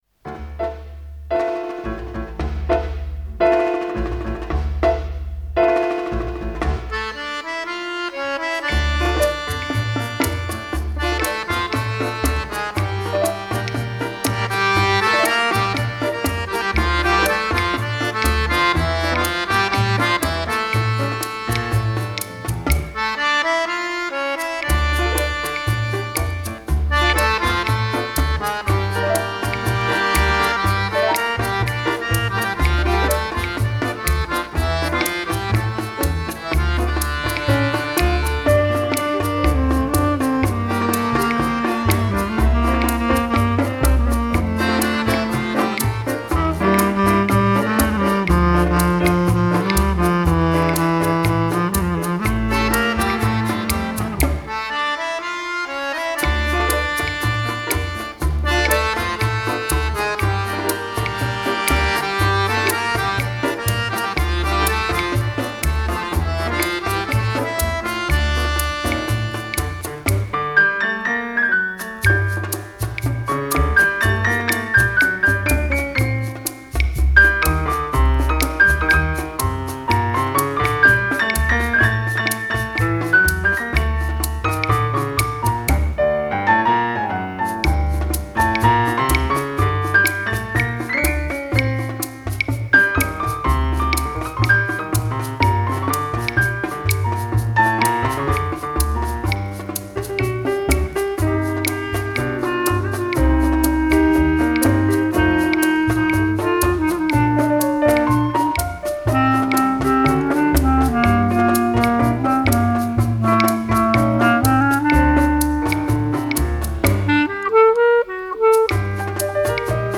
с телефона загрузил с браком